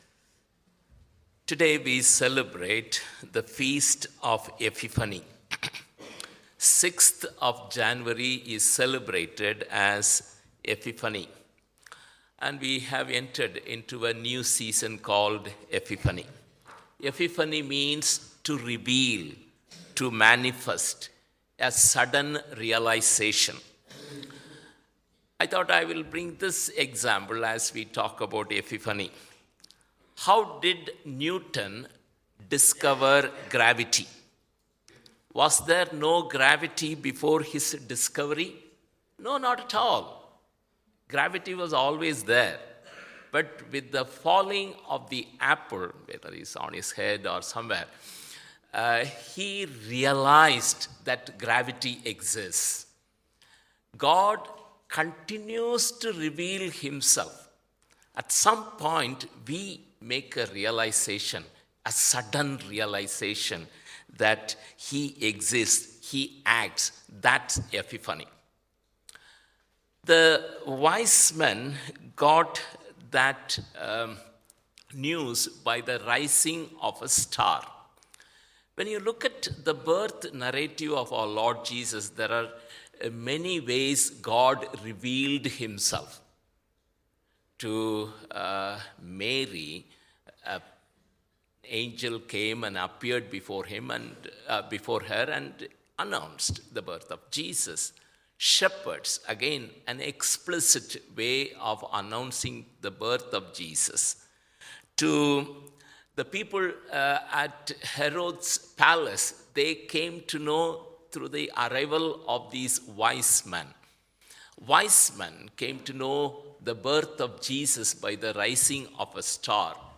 1 Sermon – January 7, 2024 26:08